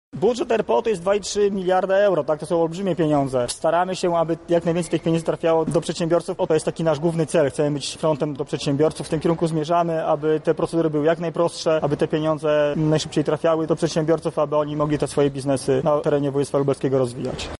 Uczestnicy konferencji „Instrumenty Wsparcia dla Przedsiębiorstw” dowiedzieli się, jakie są formy pomocy dla lubelskich firm.
Dotacje można otrzymać m. in. od Regionalnego Programu Operacyjnym Województwa Lubelskiego. Więcej na ten temat mówił Sebastian Trojak, członek Zarządu Województwa Lubelskiego: